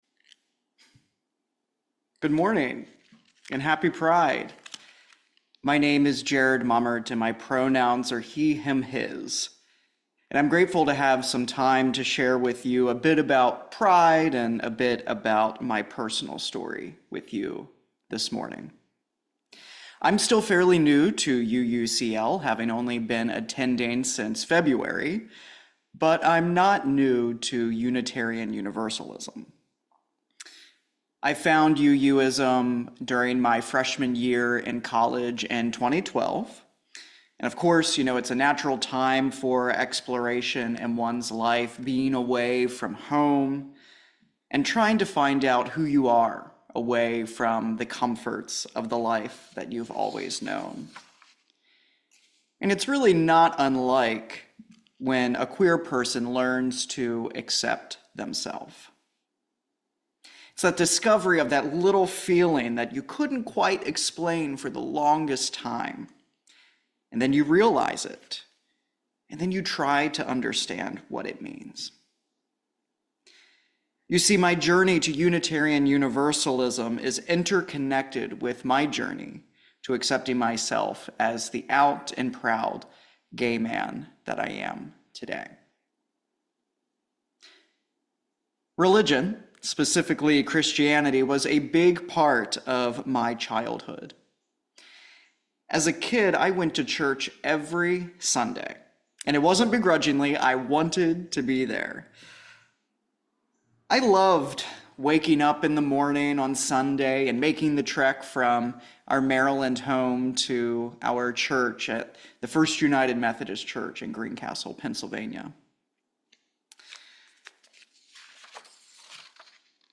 In !Audio Sermon, Acceptance, Community, Inherent Worth